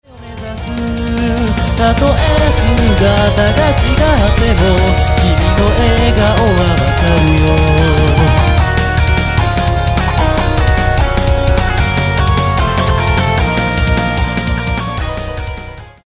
・オリジナルボーカルシングル